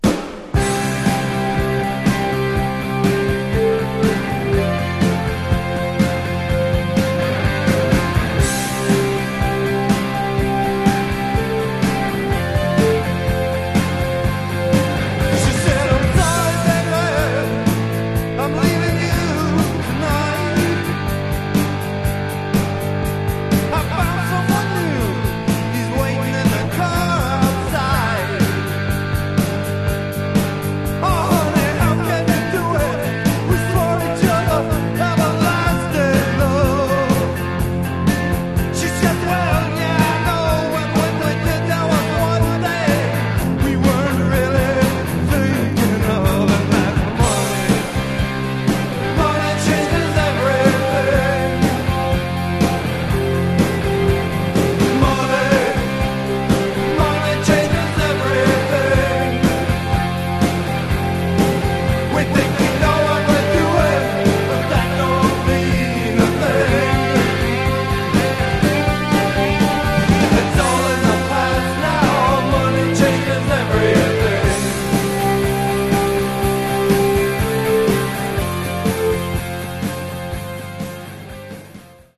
Genre: Punk/Grunge